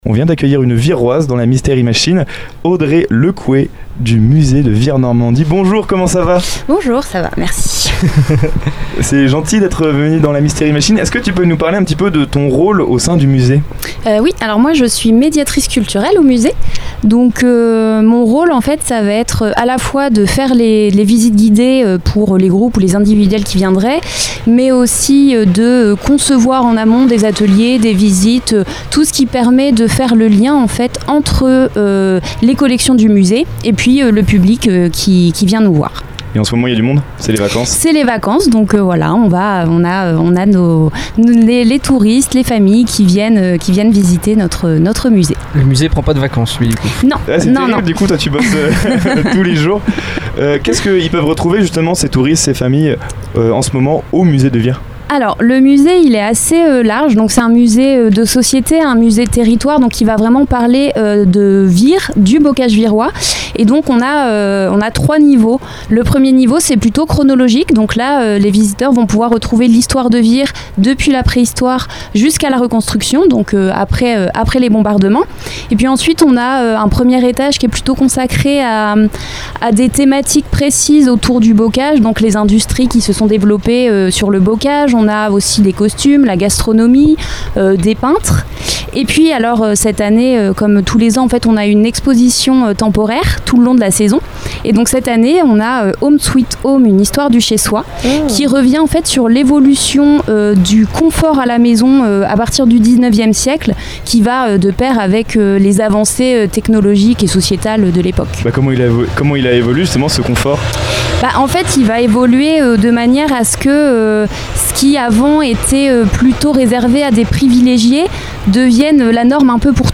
Au micro, elle nous présente les collections du musée, l’histoire du territoire et les expositions proposées au public. Elle évoque également le rôle essentiel du musée dans la transmission de la mémoire locale et la valorisation du patrimoine virien.